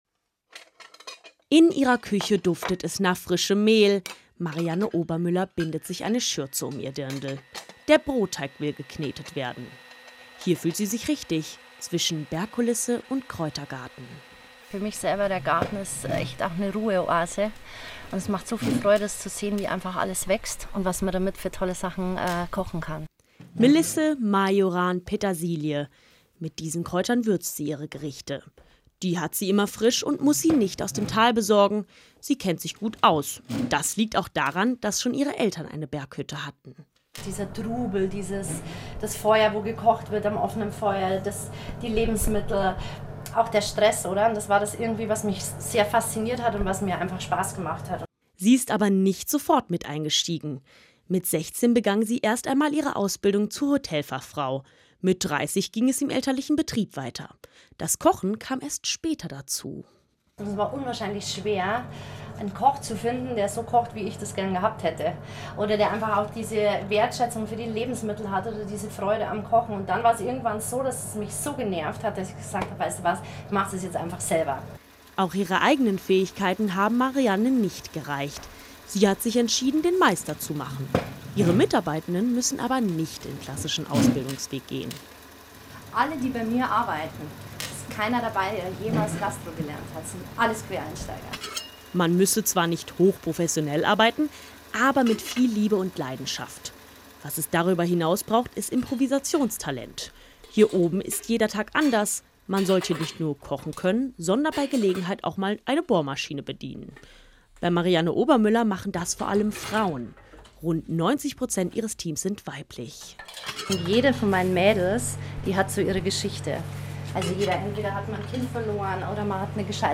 Zusätzlich haben wir für das Bayern 2 Rucksackradio Beiträge produziert, die von unseren „Wie werde ich...?“-Berufen am Berg erzählen.